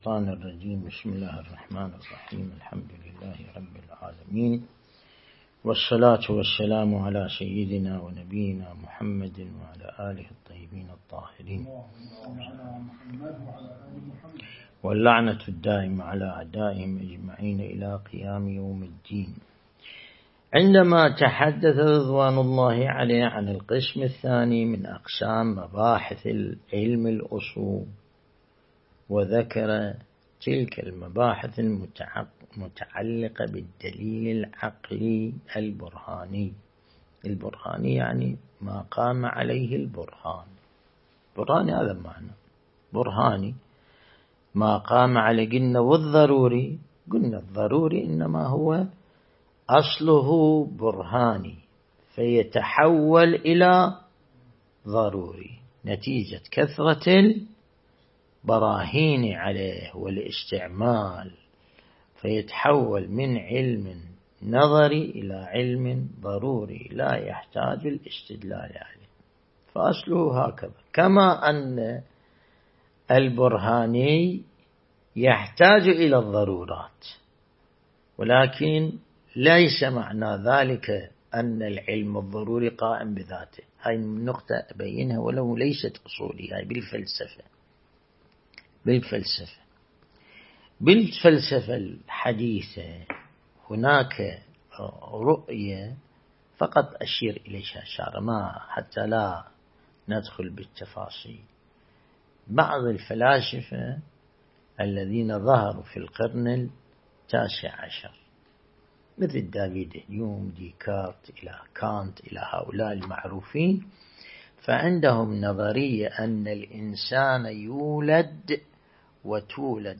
درس البحث الخارج الأصول (27)
النجف الأشرف